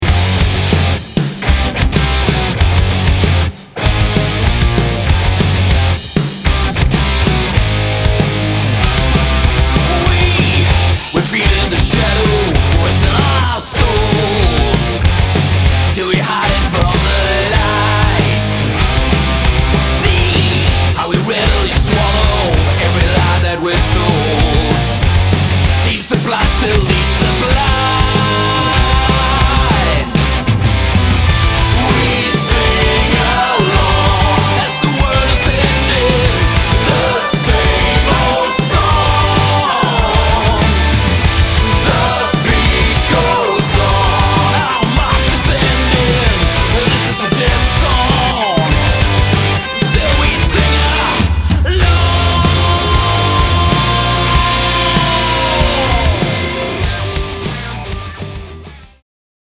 • Metal
• Rock